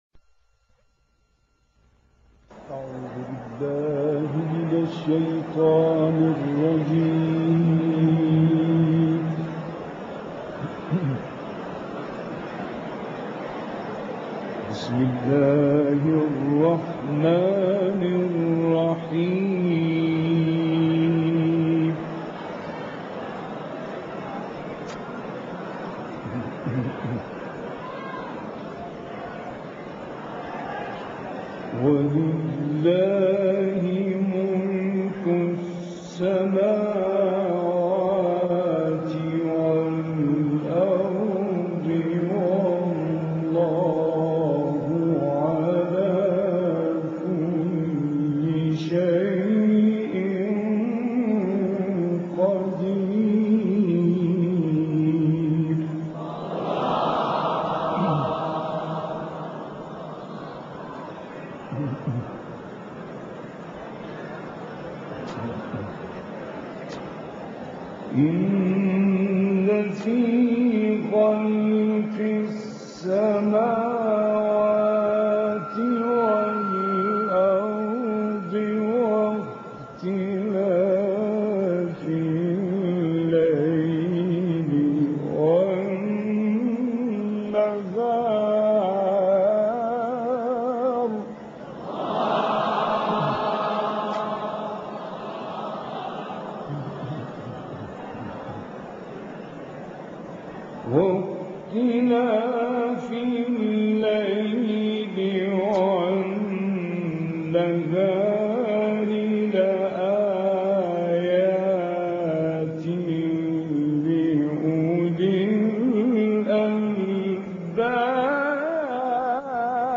تلاوت «غلوش» در مسجد طوبی تبریز
گروه شبکه اجتماعی: تلاوت سوره آل عمران با صوت راغب مصطفی غلوش که در مسجد طوبی شهر تبریز انجام شده است، می‌شنوید.
این تلاوت 25 دقیقه ای، در مسجد طوبی شهر تبریز اجرا شده است.